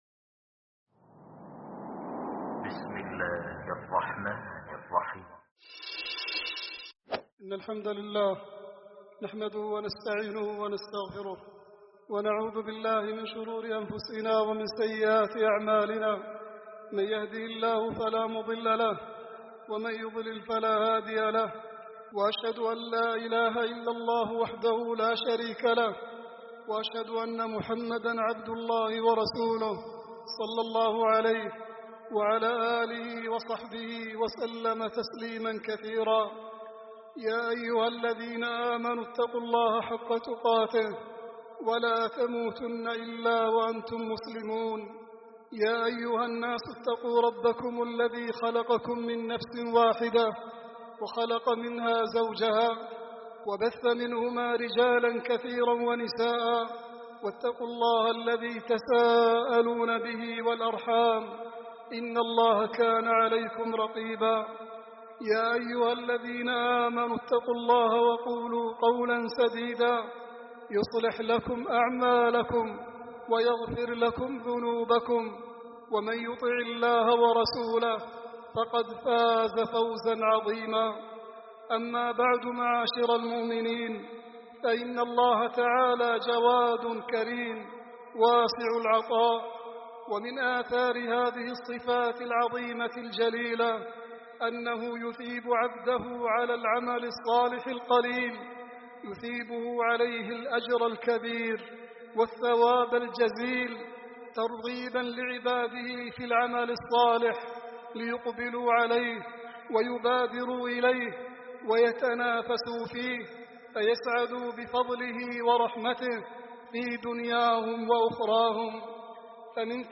خطبة
خطب الجمعة والأعياد